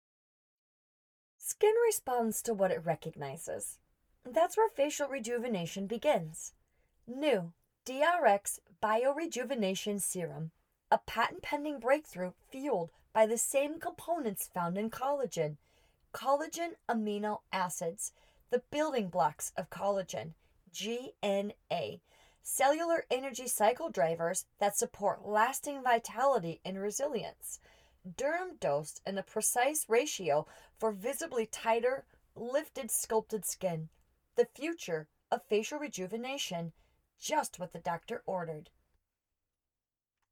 Professional-grade recording equipment and acoustically treated space